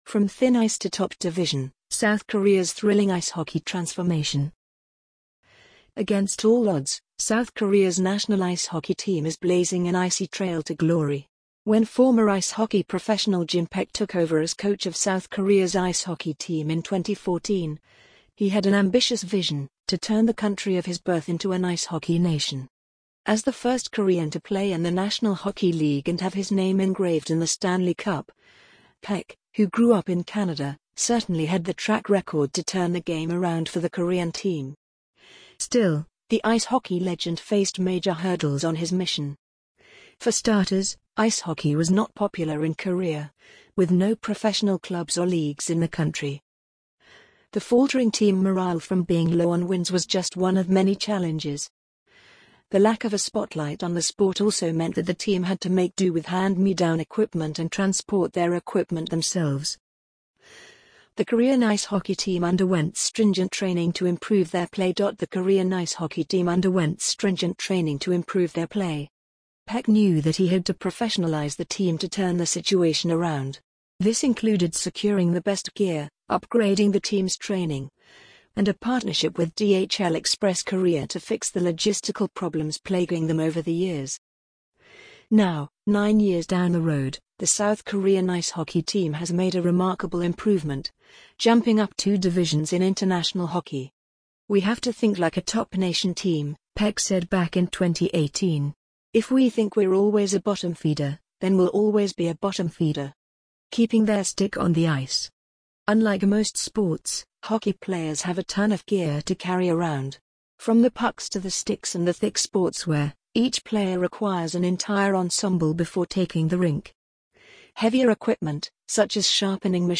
amazon_polly_45142.mp3